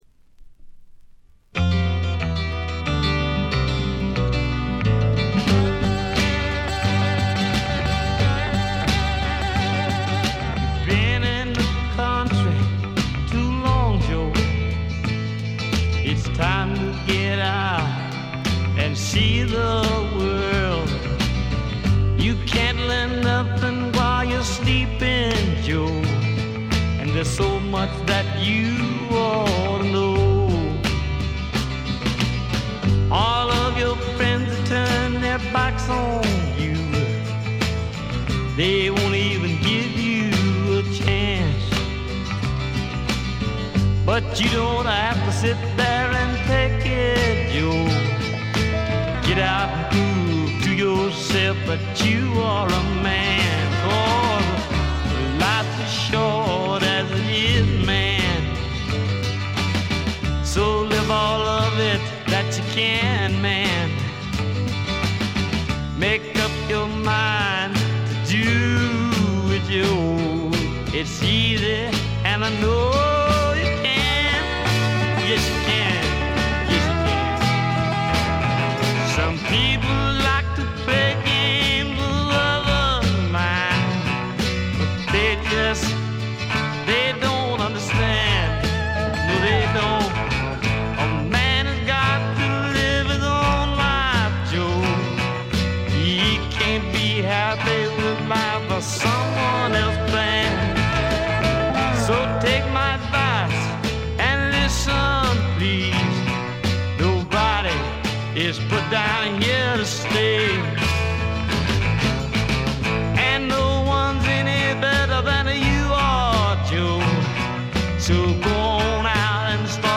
ほとんどノイズ感無し。
文句なしのスワンプ名盤。
試聴曲は現品からの取り込み音源です。